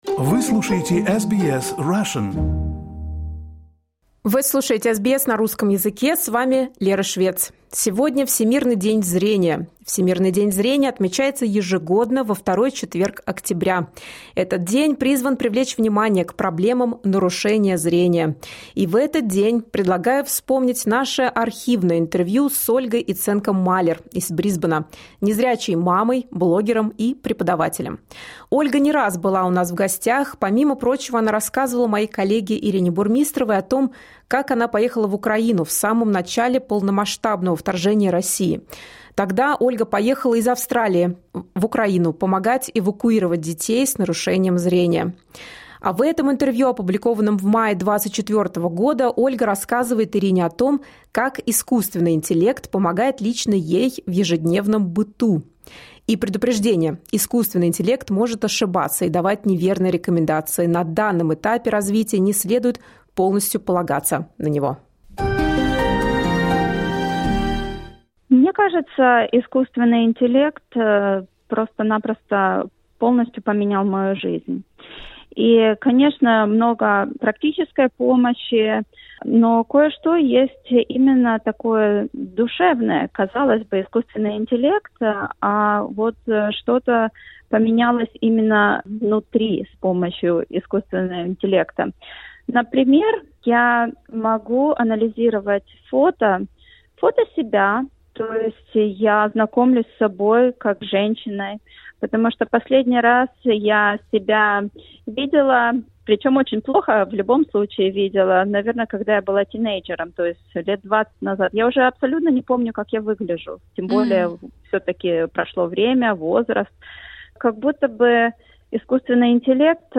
В международный день зрения вспоминаем наше архивное интервью